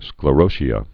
(sklə-rōshē-ə, -shə)